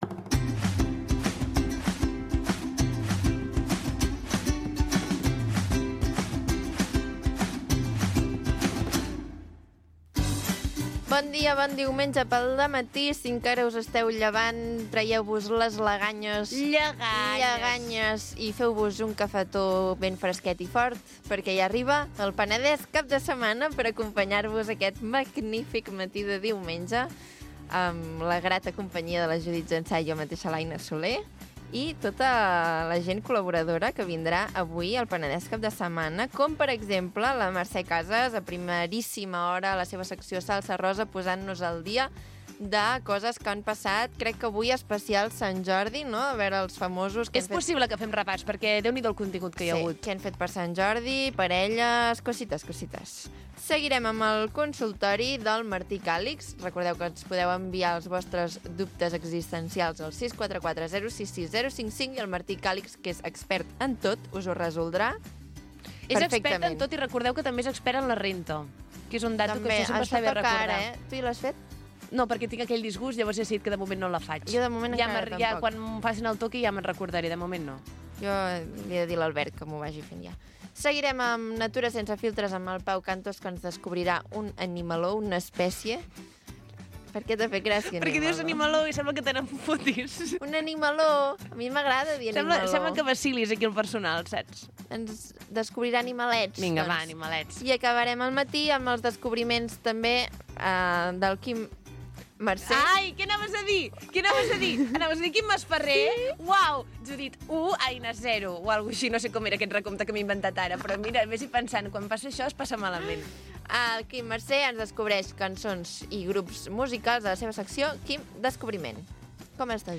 El magazín del cap de setmana